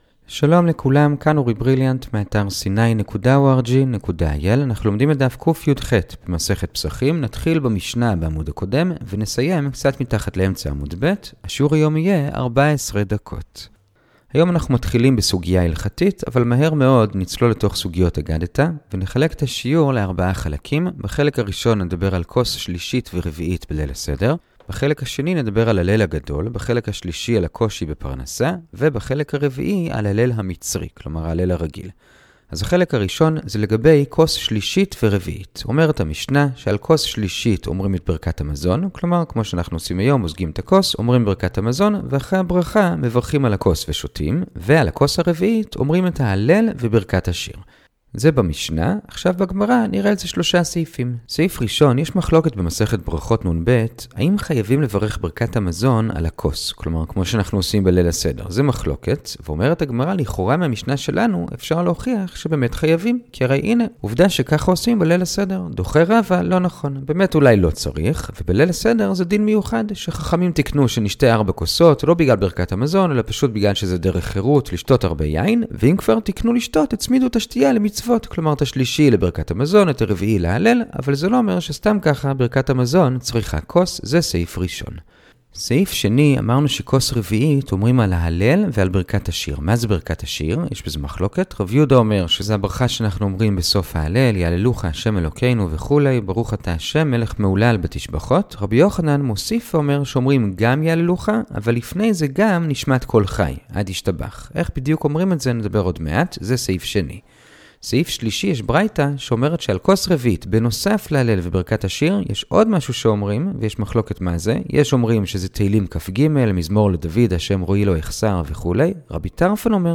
הדף היומי - פסחים קיח - הדף היומי ב15 דקות - שיעורי דף יומי קצרים בגמרא